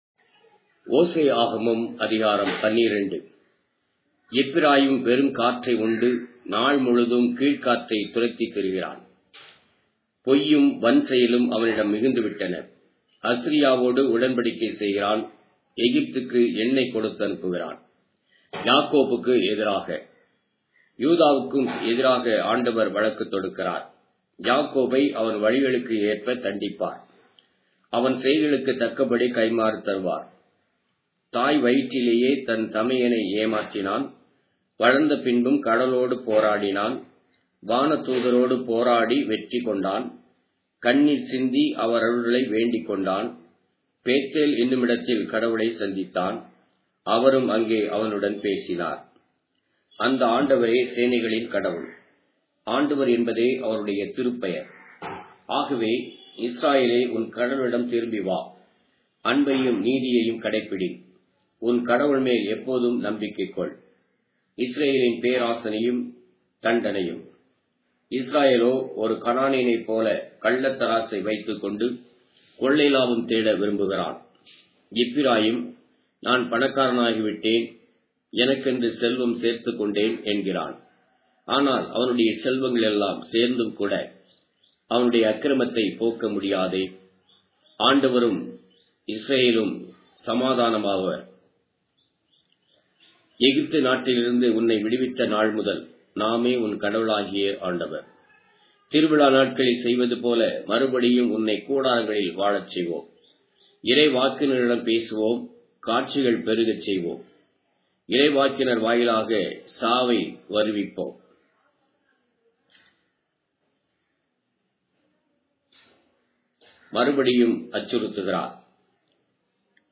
Tamil Audio Bible - Hosea 10 in Rcta bible version